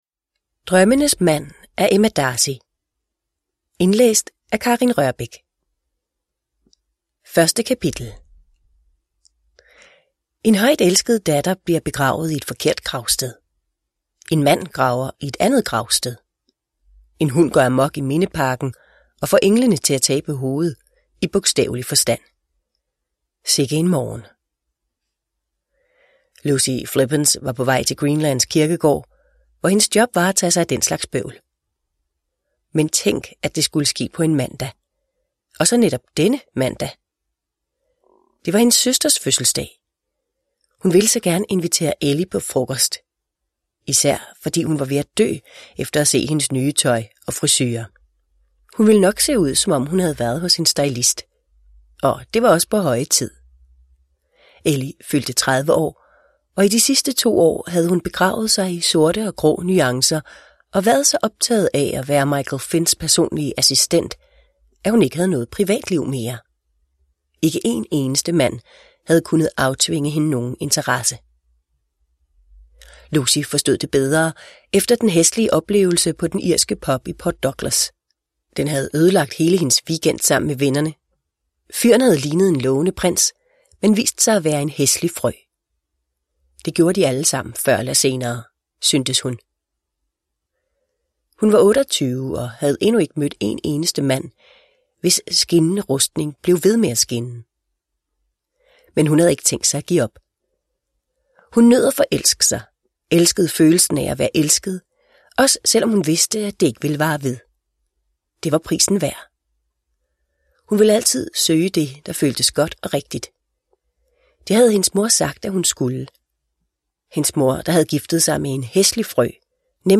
Drømmenes mand – Ljudbok – Laddas ner